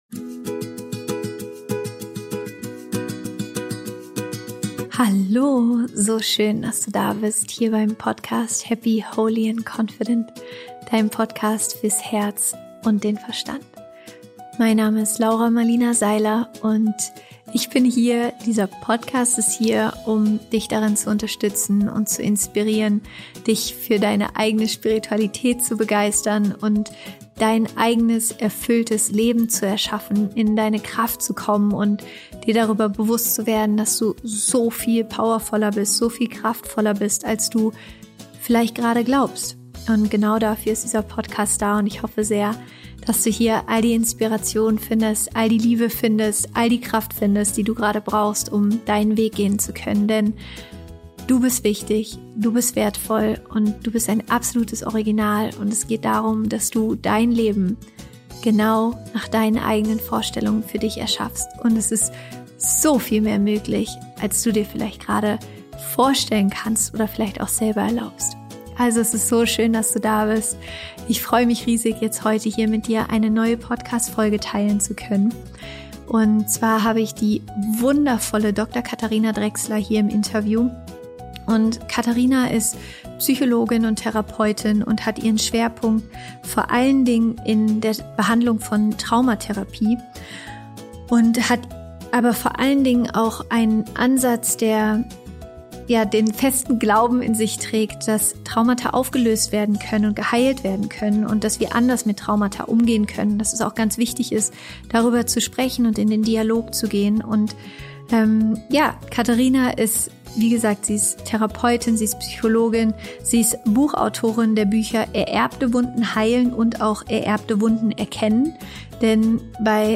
Im Interview sprechen wir darüber, was ein Trauma ist und wie wir damit umgehen können.